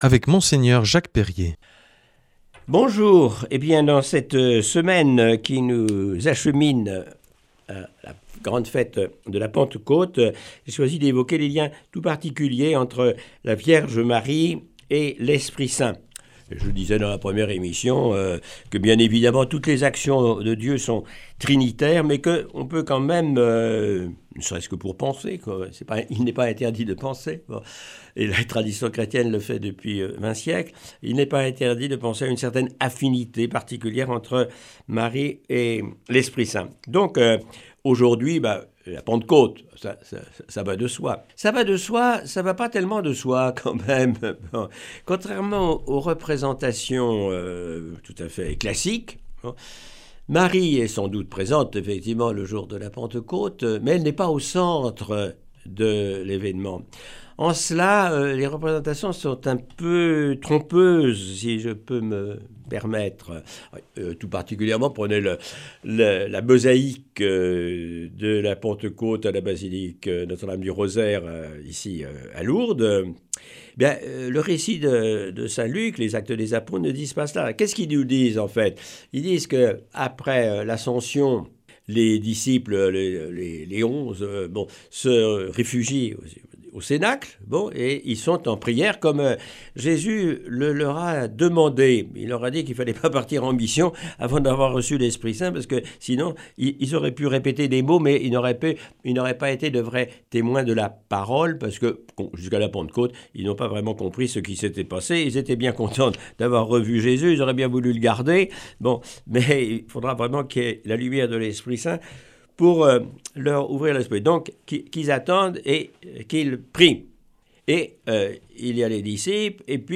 Enseignement Marial du 15 mai
Aujourd’hui avec Mgr Jacques Perrier.